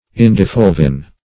Search Result for " indifulvin" : The Collaborative International Dictionary of English v.0.48: Indifulvin \In`di*ful"vin\, n. [Indican + L. fulvus reddish yellow.]